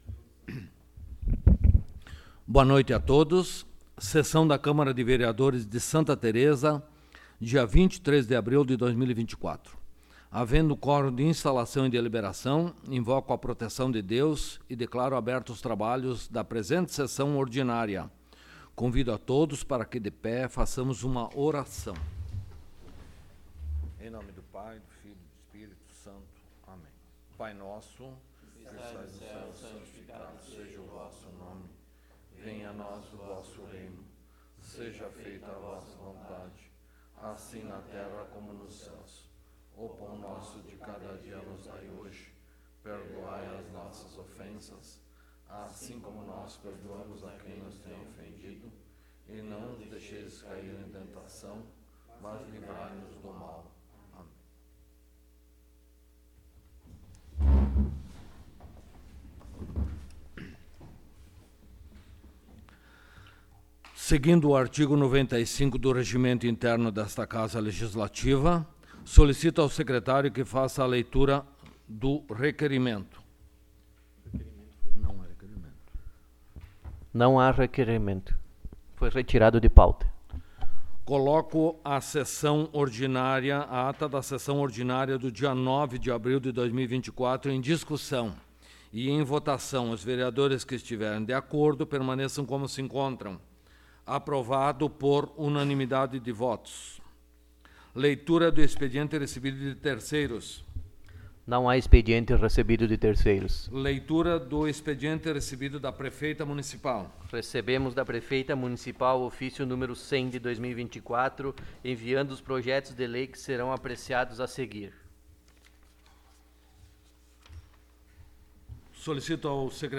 Áudio da Sessão
Local: Câmara Municipal de Vereadores de Santa Tereza